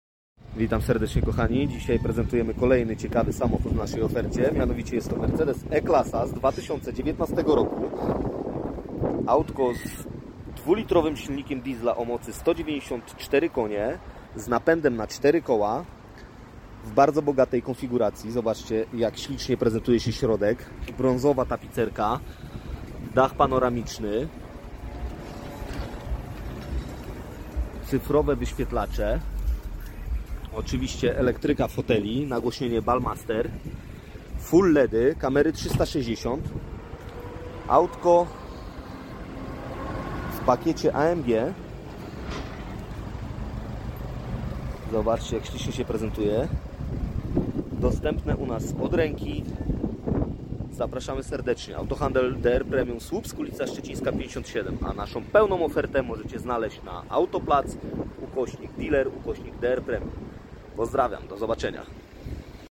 Mercedes Benz E220 CDI 2019 rok. sound effects free download